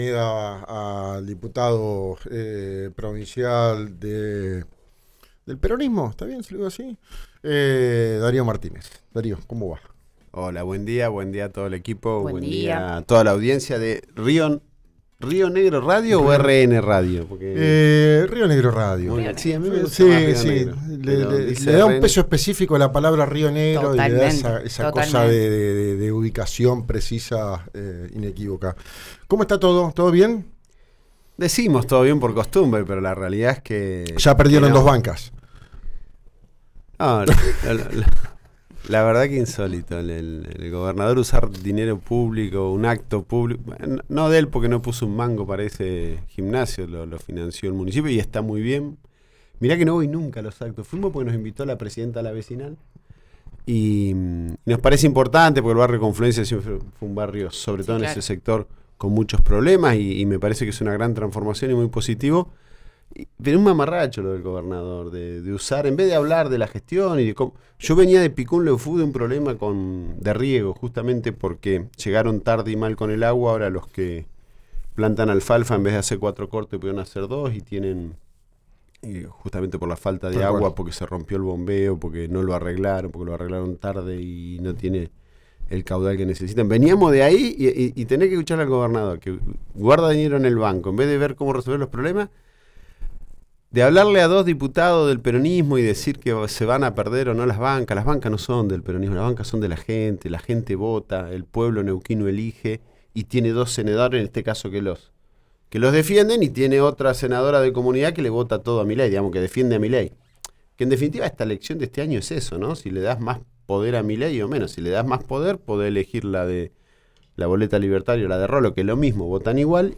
Escuchá a Darío Martínez, diputado de Unión por la Patria, por RÍO NEGRO RADIO: